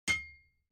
دانلود آهنگ تصادف 13 از افکت صوتی حمل و نقل
دانلود صدای تصادف 13 از ساعد نیوز با لینک مستقیم و کیفیت بالا